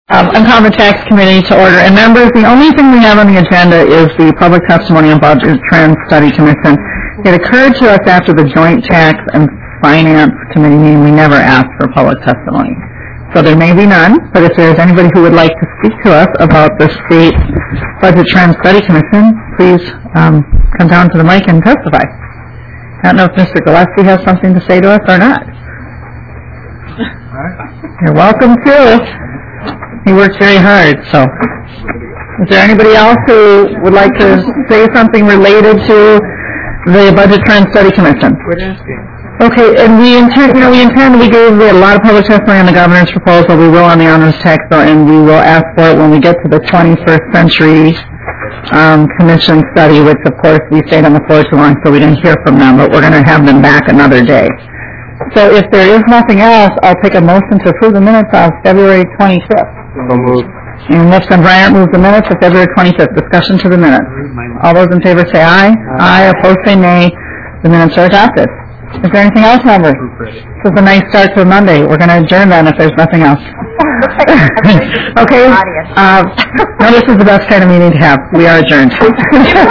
Public testimony on Budget Trends Study Commission